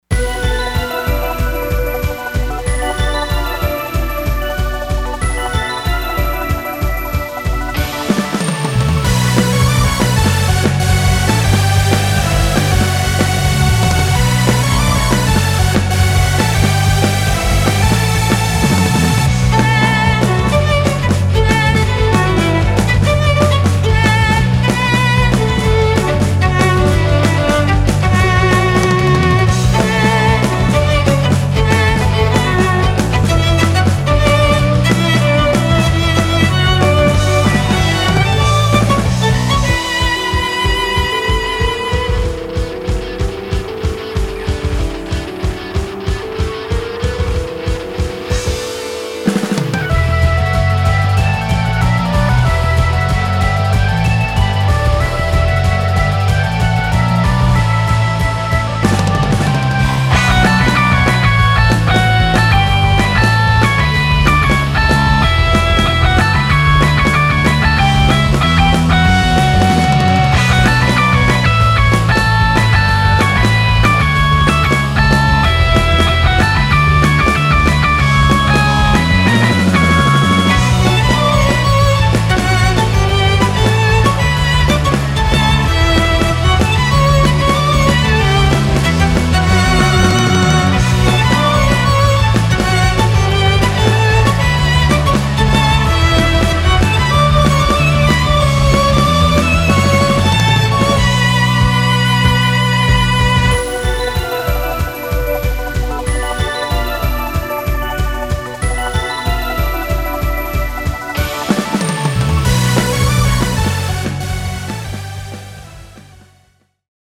フリーBGM バトル・戦闘 バンドサウンド